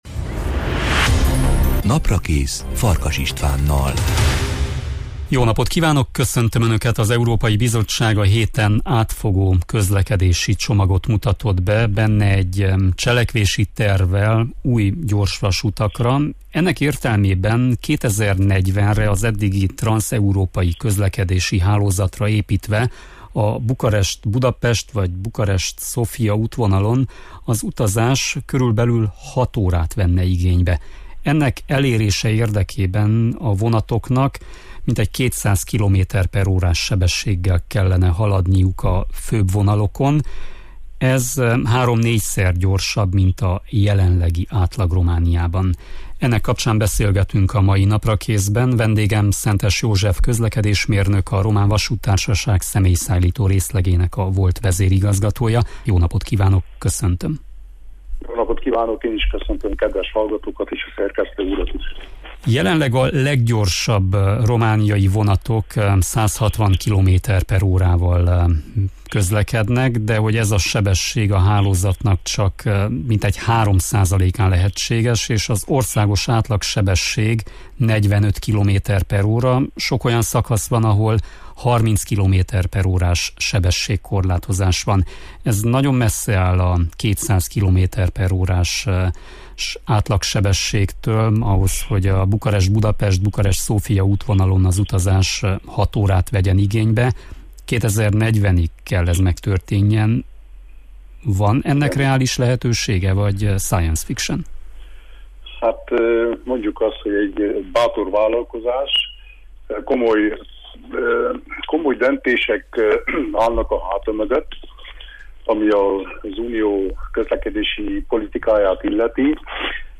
Ezekről a kérdésekről beszélgetünk a mai Naprakészben